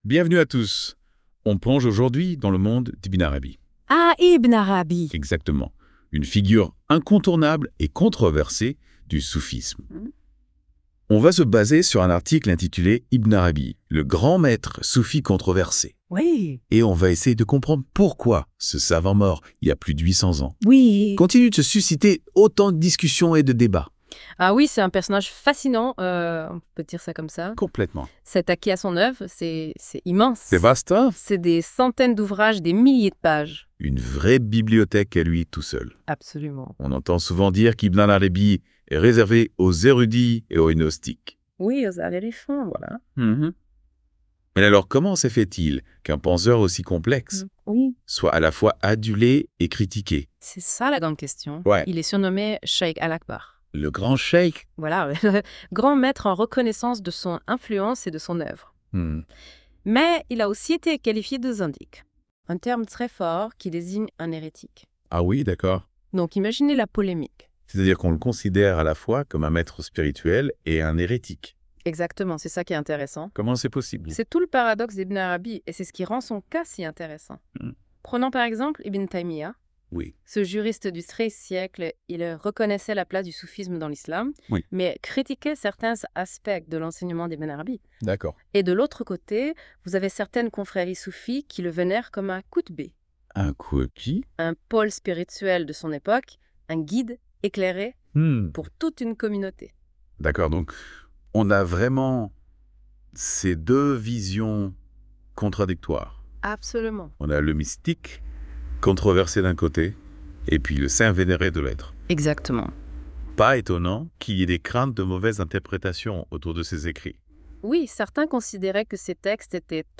Débat sur l'œuvre d'Ibn Arabi, un maître soufi controversé..wav (41.24 Mo)